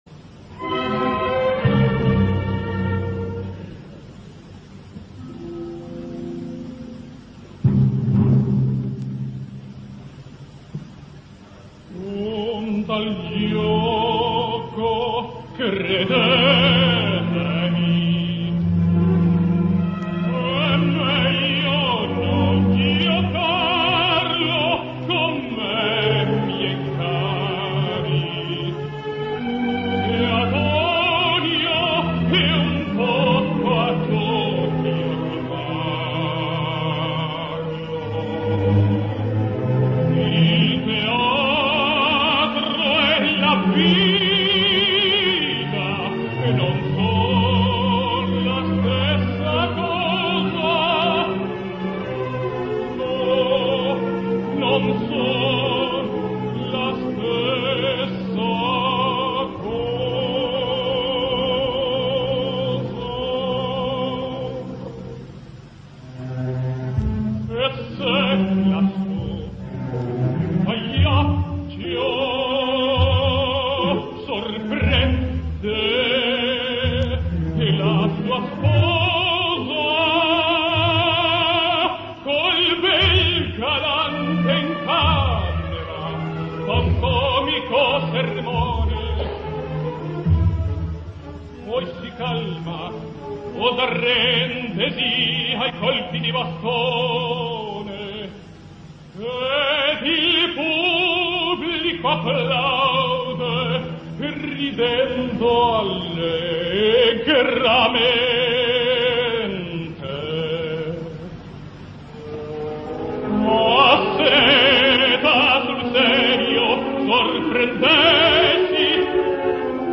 Alfredo Kraus sings Pagliacci: